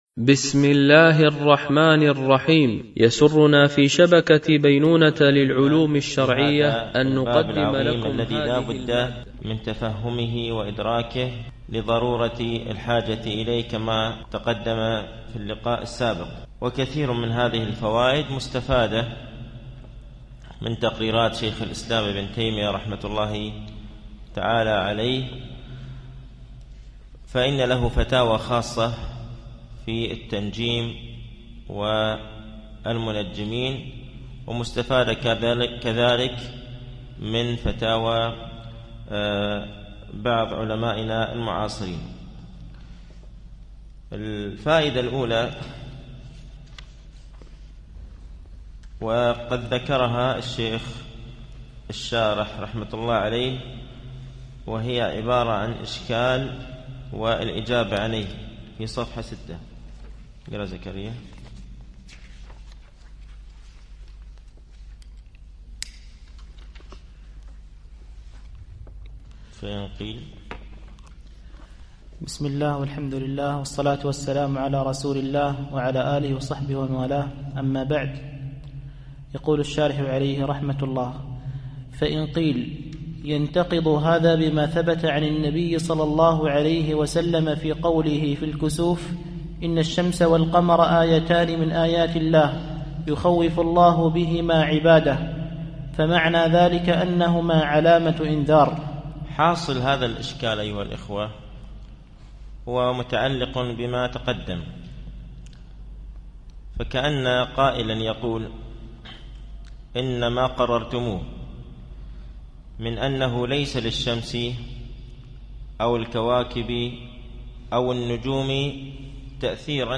MP3 Mono 22kHz 62Kbps (VBR)